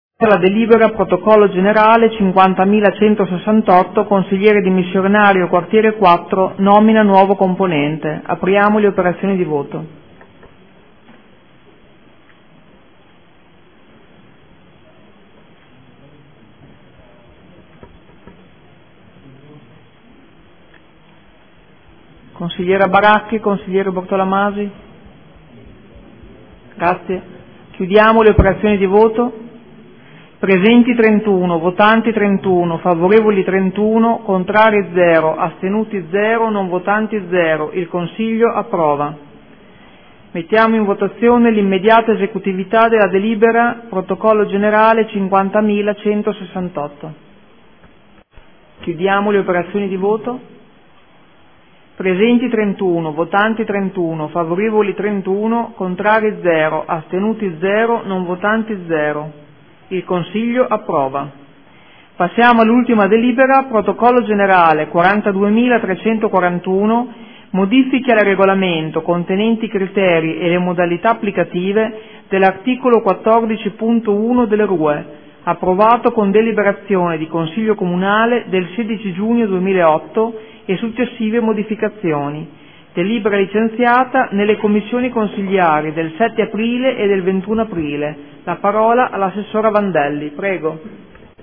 Seduta del 22 aprile. Proposta di deliberazione: Consigliere dimissionario Quartiere 4 – Nomina nuovo componente. Votazione